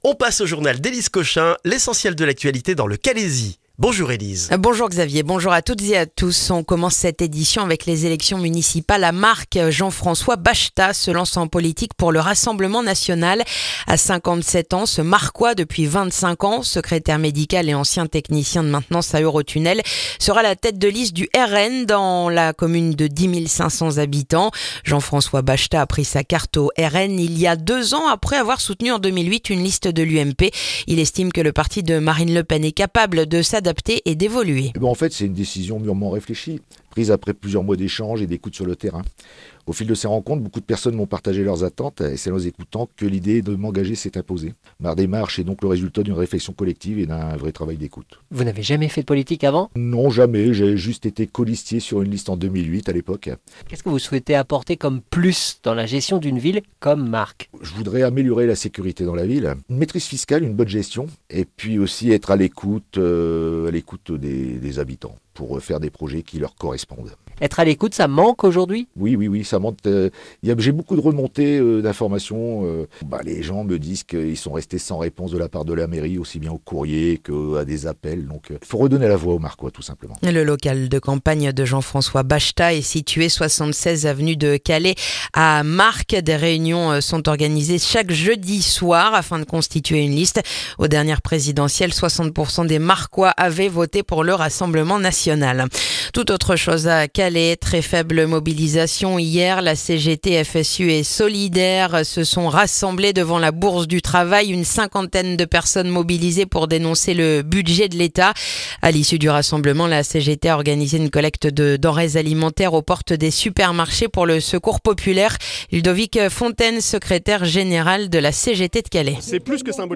Le journal du mercredi 3 décembre dans le calaisis